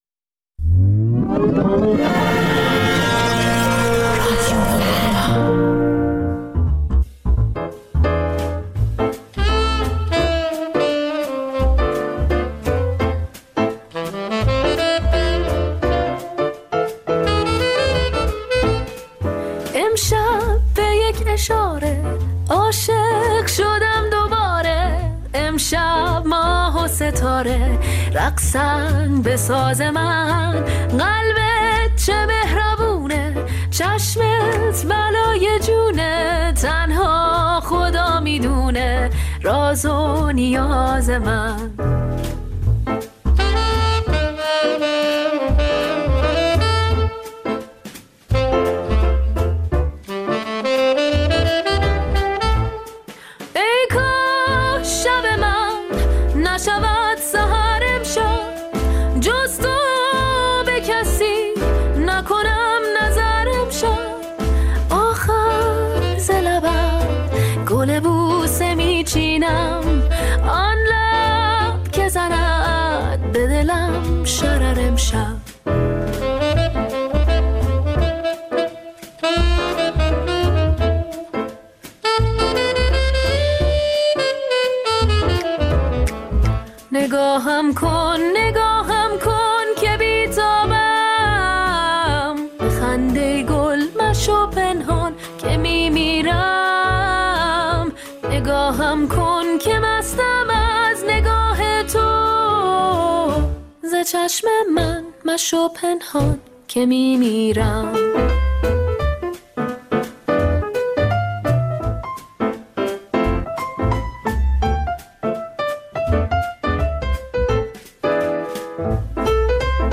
دقایقی با موسیقی جز سول و بلوز